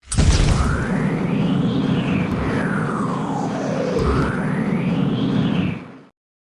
Jetpack Ignite and Fly
SFX
yt_9Miwb_qgQZQ_jetpack_ignite_and_fly.mp3